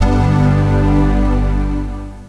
Start-Up Sounds:
Start-Up_Mac_QuadraAV ...............Macintosh Quadra840AV